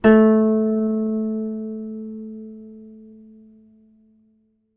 ZITHER A 1.wav